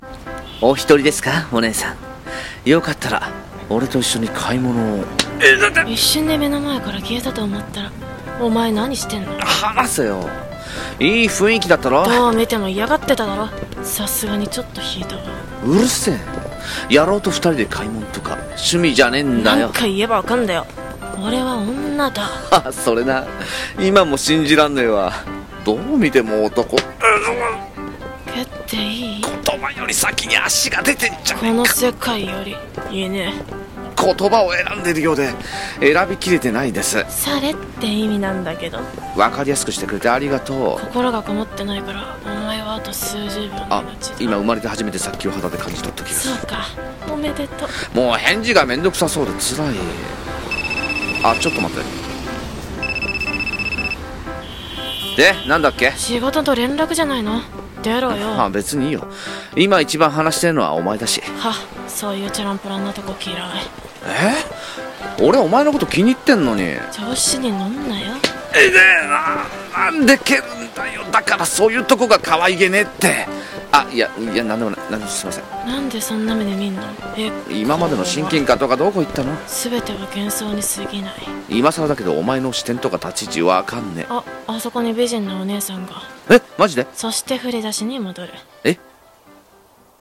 声劇『無限ループ』